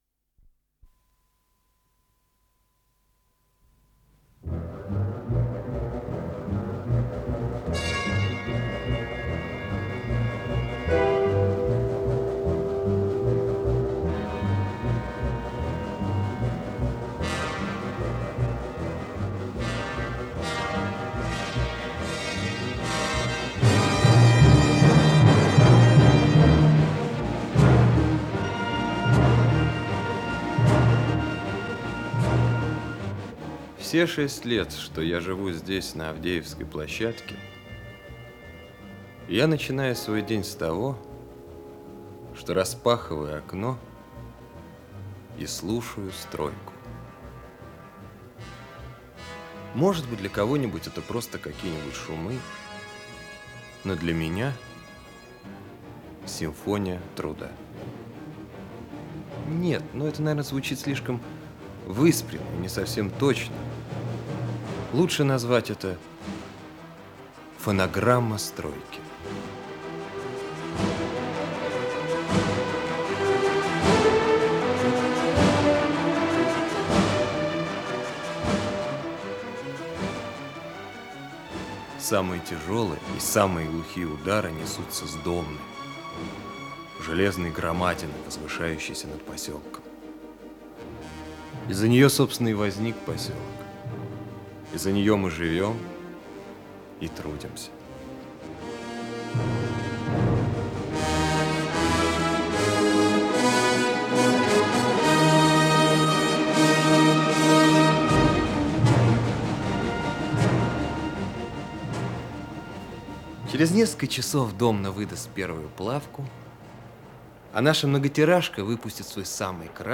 Исполнитель: Артисты московских театров
Радиоспектакль по мотивам одноимённого романа, часть 1-я